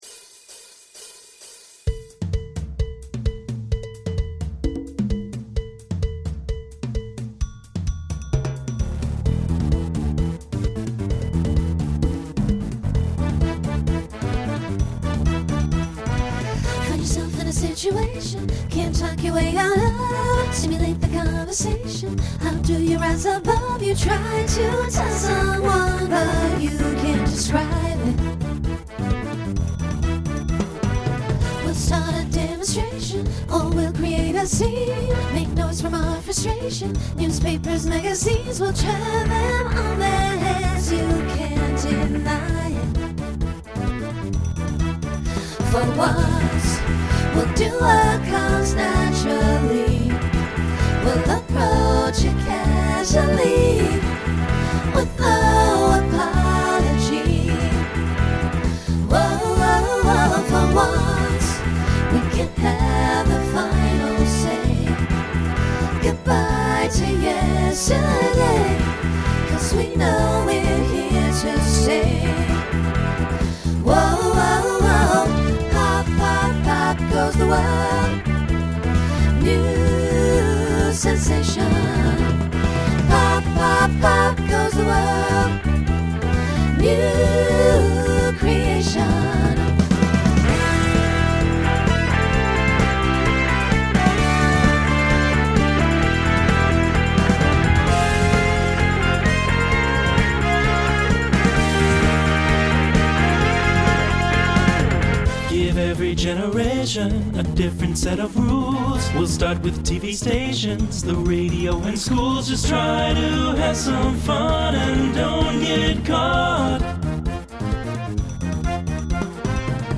Starts SSA, then TTB, then SATB.